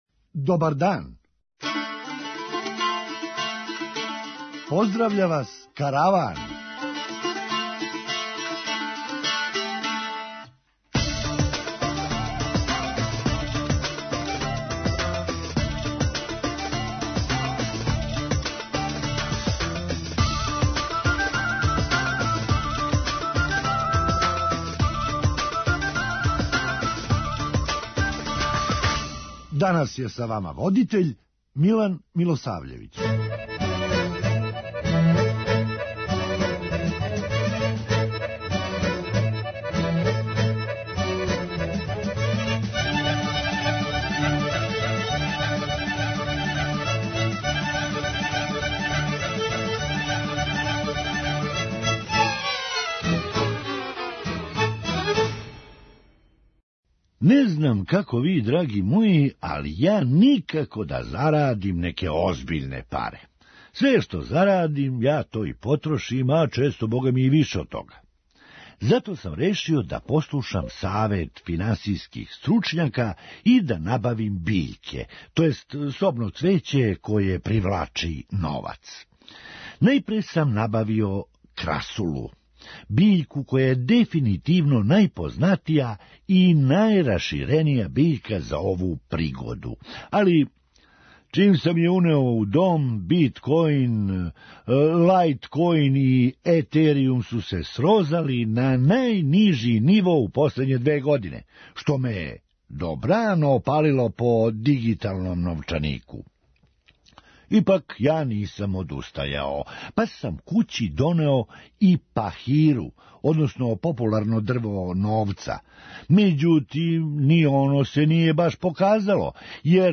Хумористичка емисија